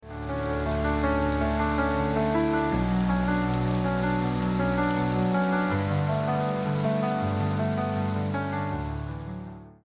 Moonlight drapes the rainy forest sound effects free download
Moonlight drapes the rainy forest path as nature hums its midnight lullaby.